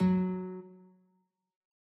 guitar.ogg